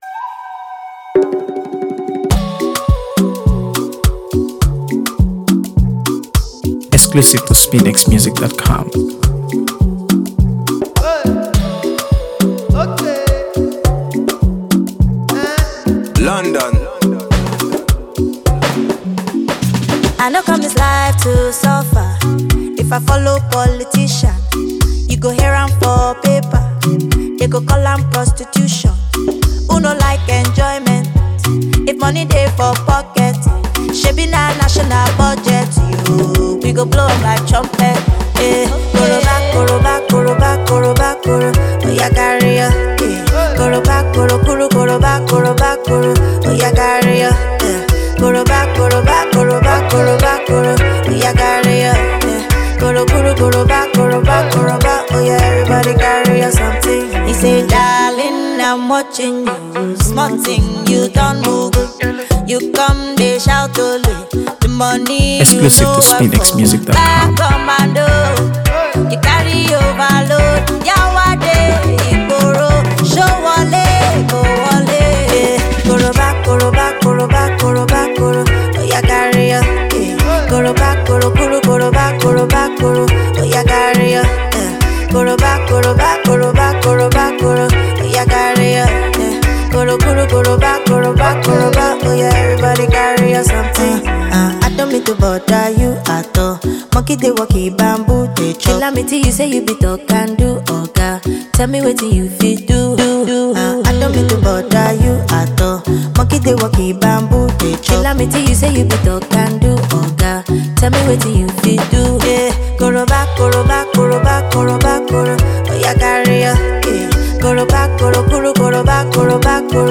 AfroBeats | AfroBeats songs
a catchy and upbeat tune